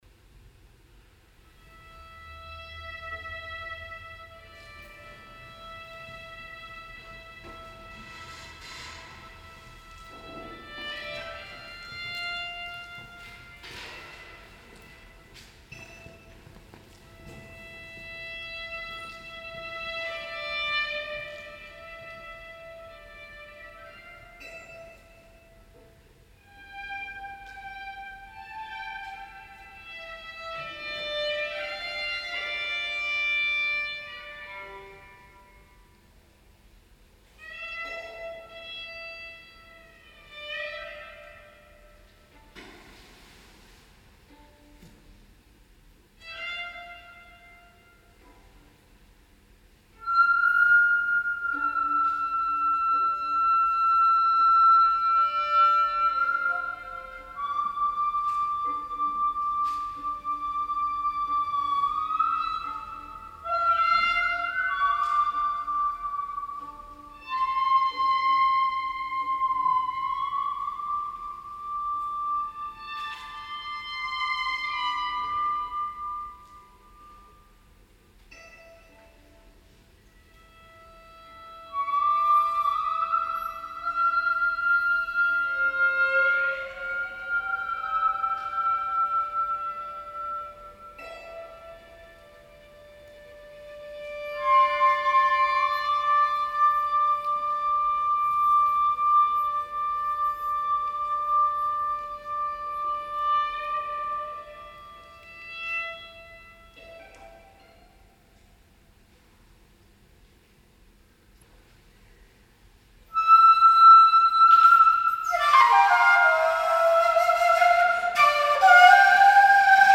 Shakuhachi
violin